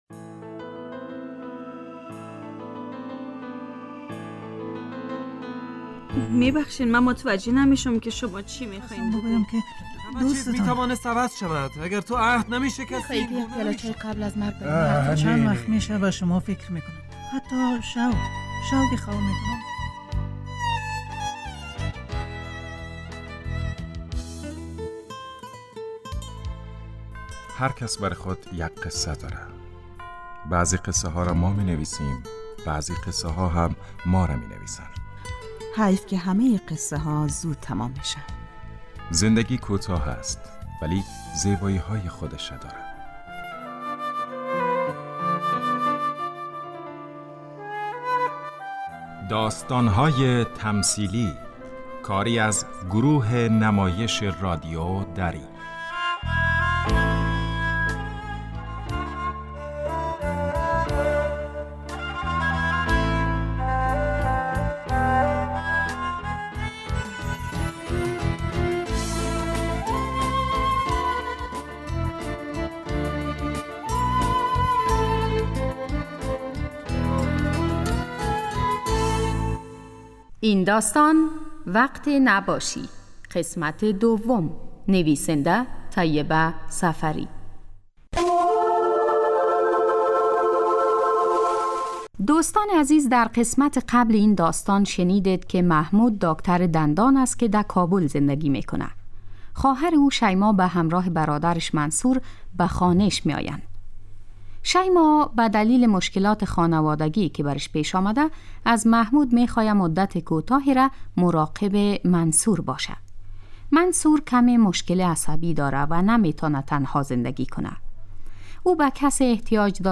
داستان تمثیلی